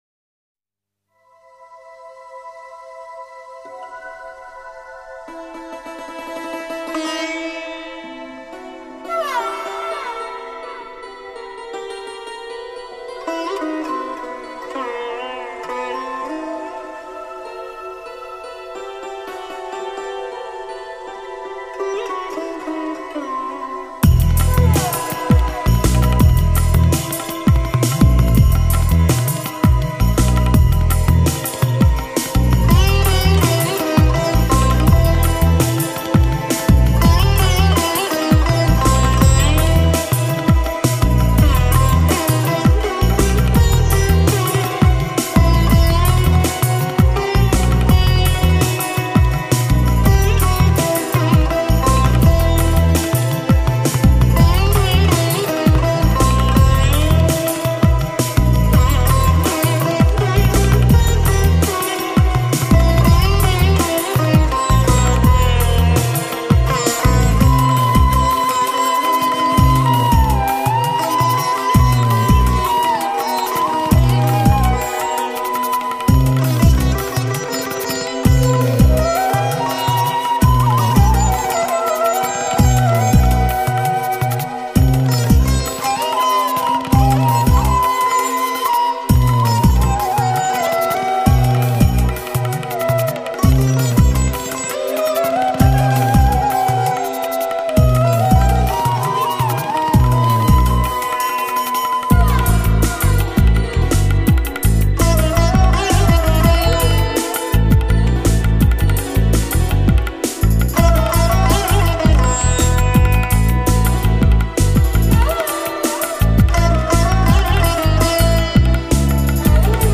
这张专辑收录的曲子，是东洋与西洋的长年的融合，亚洲的乐器和西洋乐器的合作为宗旨